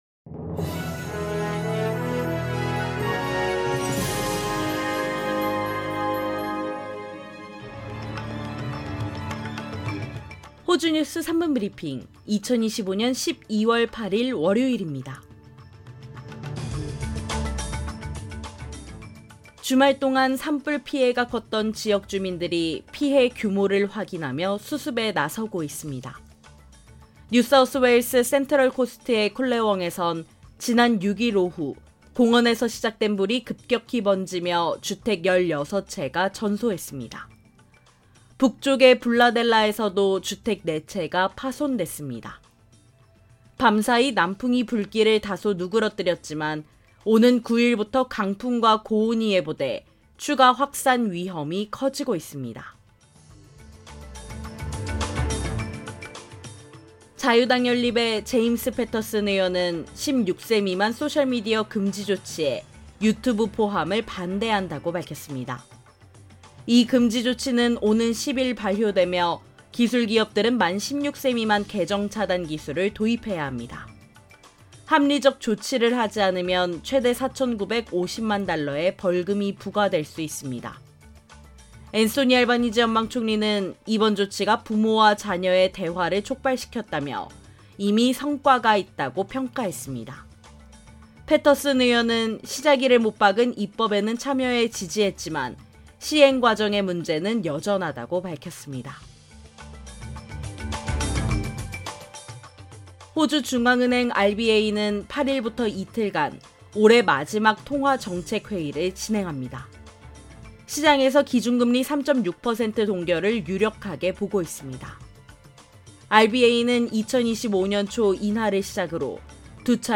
호주 뉴스 3분 브리핑: 2025년 12월 8일 월요일